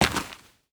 Dirt footsteps 8.wav